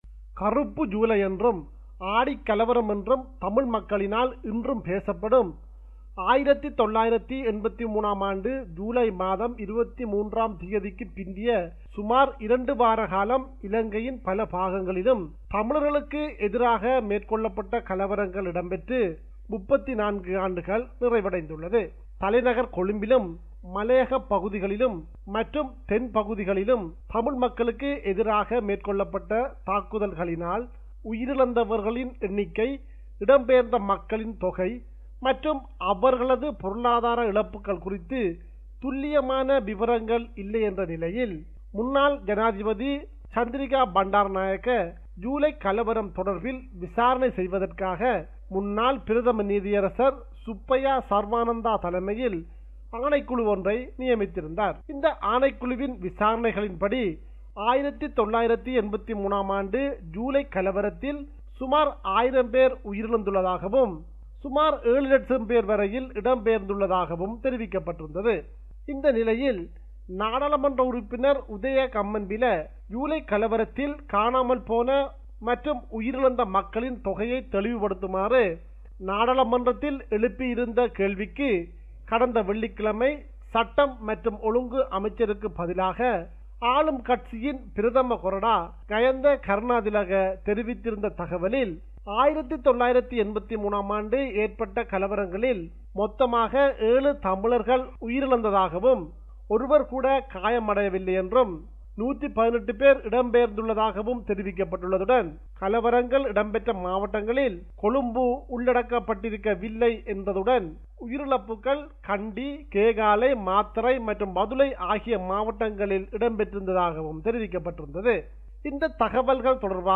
Tamil News